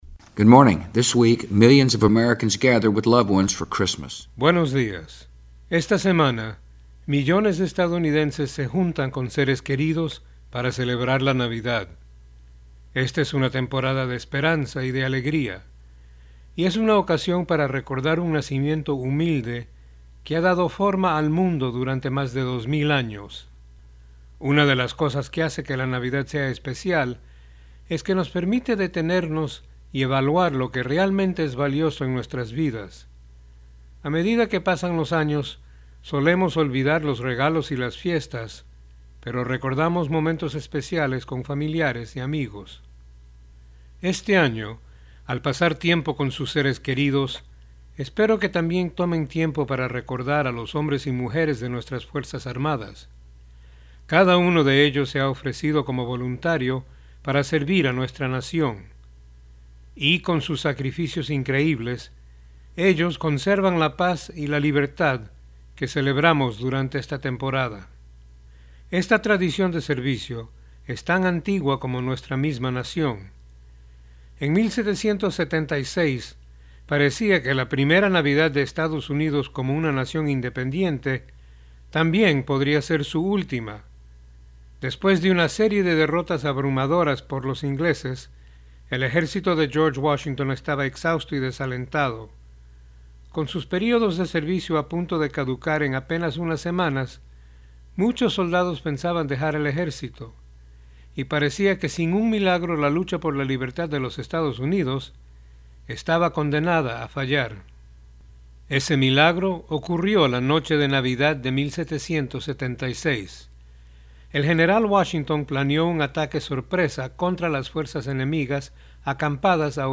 Discurso Radial del Presidente a la Nación